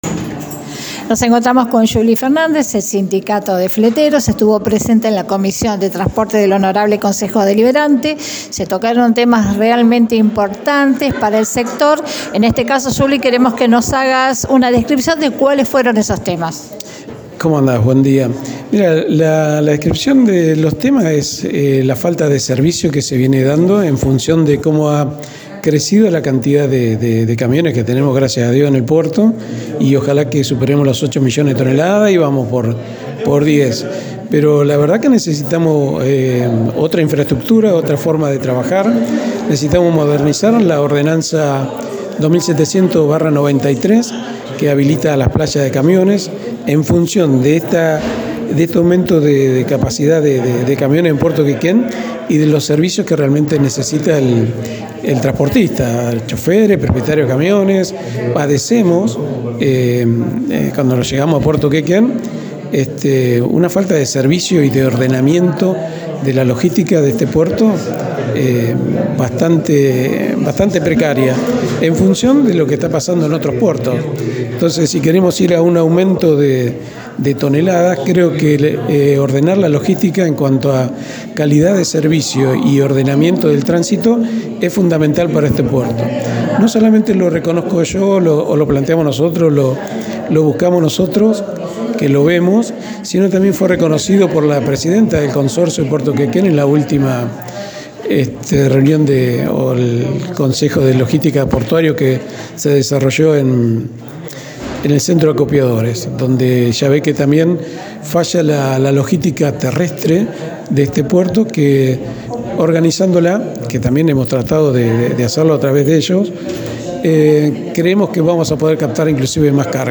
En diálogo con Aires de la Ciudad